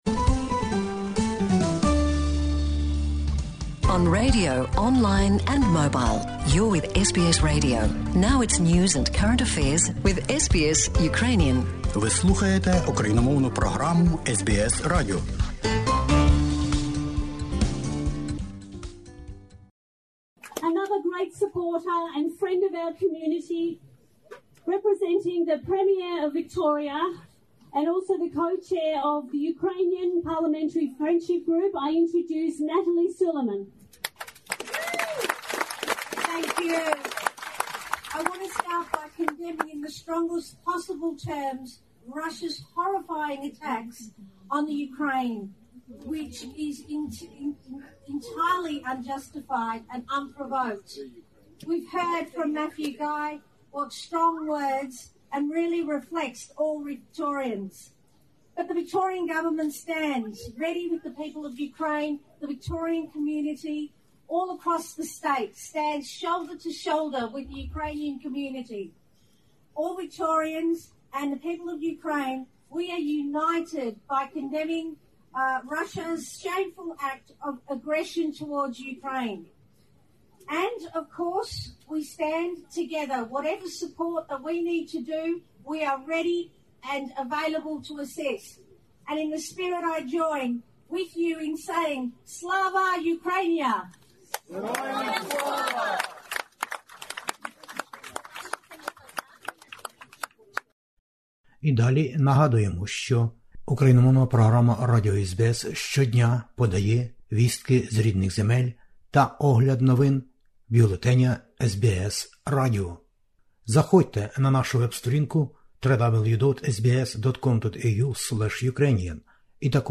Natalie Suleyman MP - Anti-war protest in Melbourne against invasion of Ukraine, 27/02/2022.